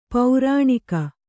♪ paurāṇika